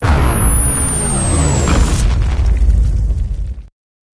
sunstrike Meme Sound Effect
This sound is perfect for adding humor, surprise, or dramatic timing to your content.